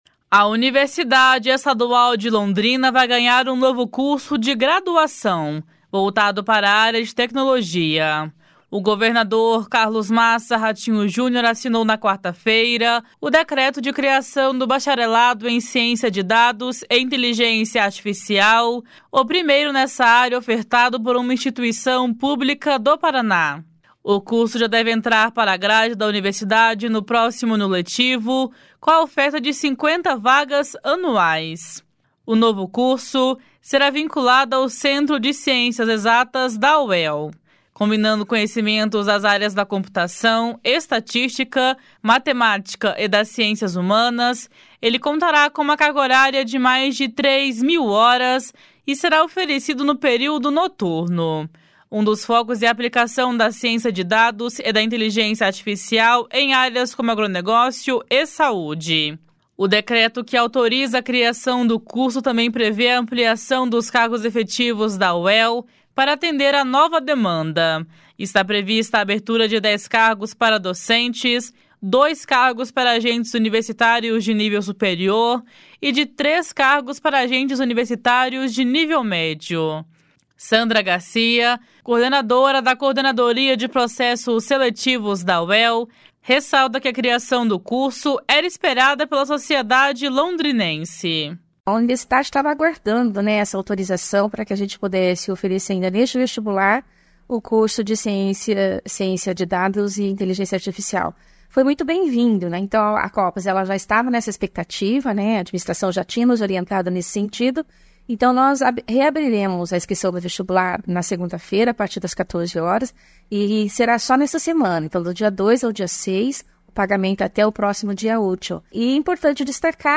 O secretário estadual da Ciência, Tecnologia e Ensino Superior, Aldo Bona, destacou que a graduação nessa área é oferecida atualmente apenas em universidades particulares do Paraná, e que a oferta em uma instituição pública colabora para melhorar o ambiente de transformação digital do Estado.